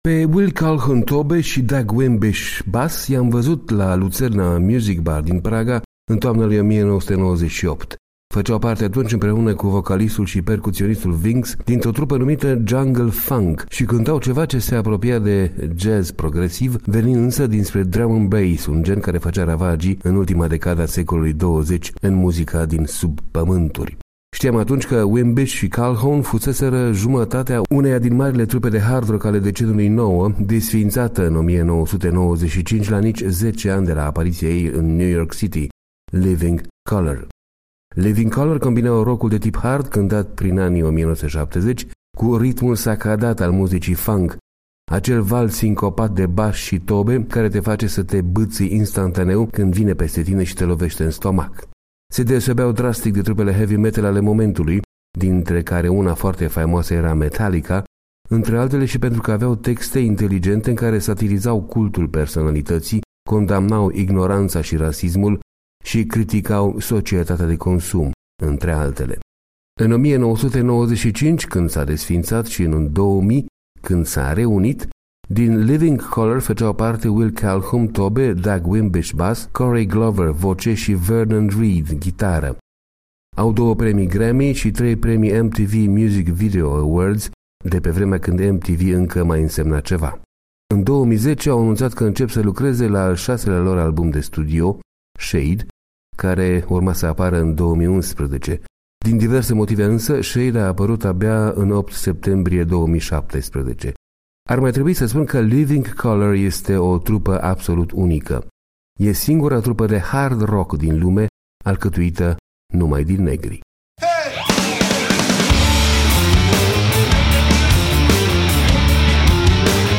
Acel val sincopat de bași și tobe care te face să te bîțîi instantaeu când vine peste tine și te lovește în stomac.
Living Colour combinau rock-ul de tip hard cântat prin anii 1970 cu ritmul sacadat al muzicii funk, acel val sincopat de bași și tobe care te face să te bîțîi instantaeu când vine peste tine și te lovește în stomac.